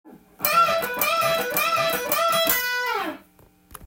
Shotgun Bluesで弾いているフレーズを耳コピしてみました。
譜面通り弾いてみました
Emペンタトニックスケールが主になります。
音符は連打する時はダウンピッキング。
エレキギターでカンタンに弾けるブルース